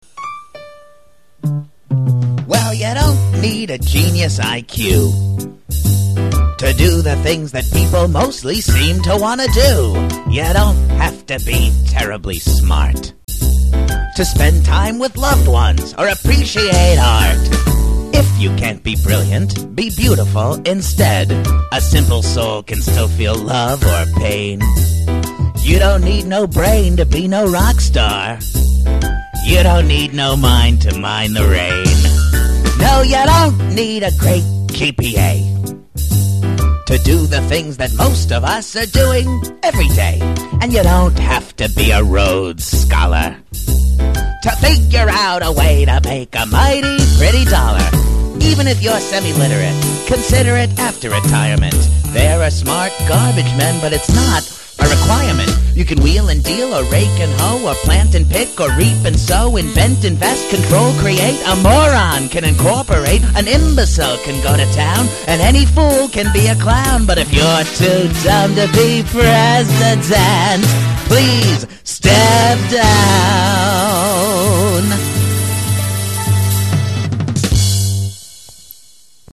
we did record this demo with him